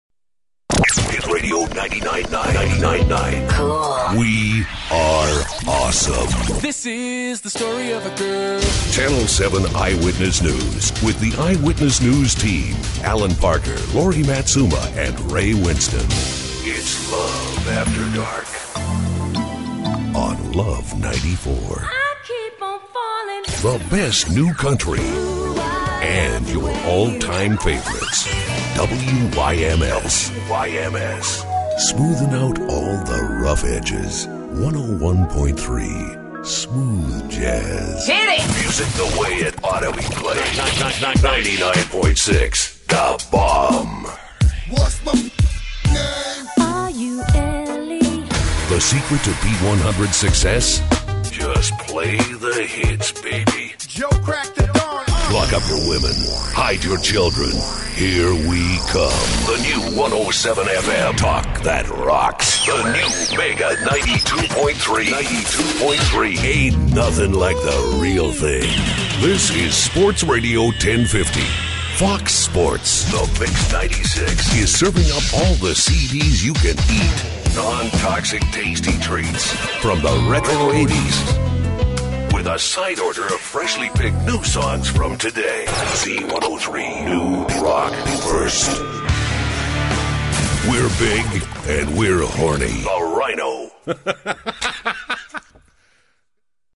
a warm yet persuasive approach that works in any genre, from smooth jazz to hard rock.
the imaging demo is a montage of station IDs and bumpers
Imaging Demo